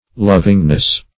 Lovingness \Lov"ing*ness\, n.